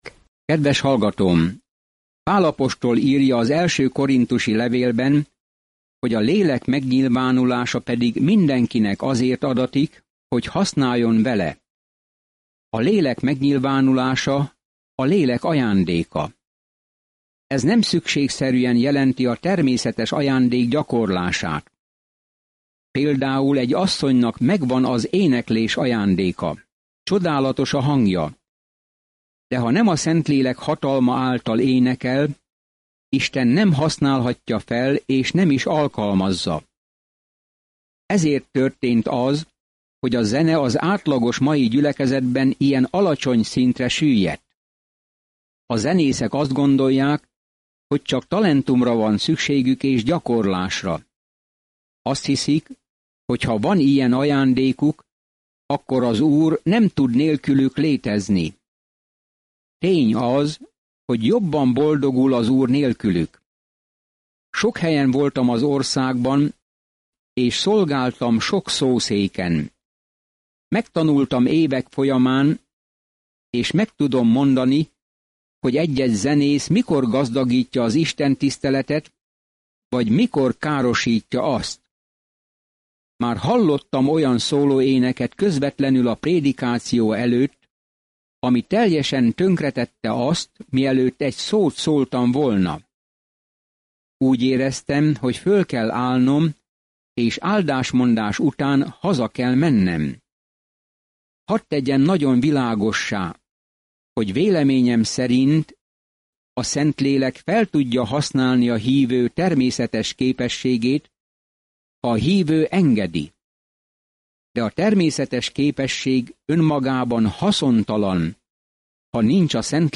A témával foglalkozik-e az első korinthusiaknak írt levél, gyakorlati gondozást és korrekciót adva a fiatal keresztények problémáira. Napi utazás az 1. korinthusi levélben, miközben hallgatja a hangos tanulmányt, és olvassa kiválasztott verseket Isten szavából.